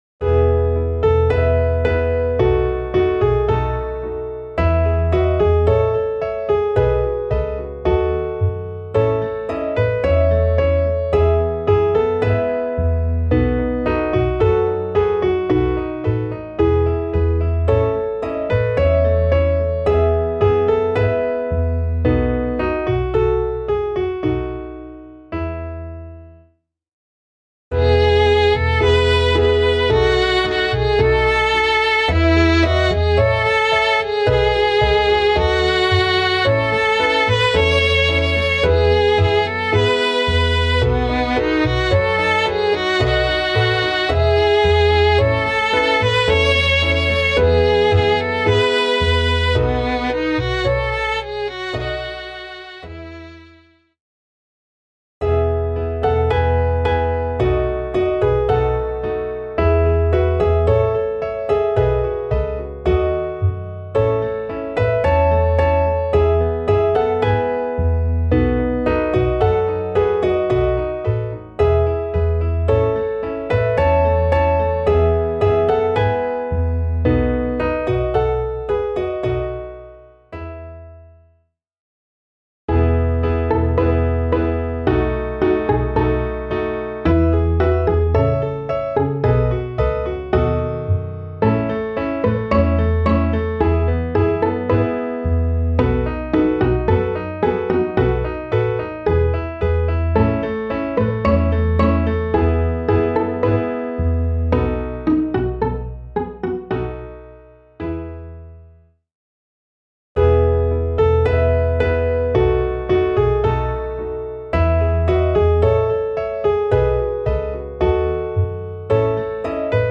Klaviersätze F bis H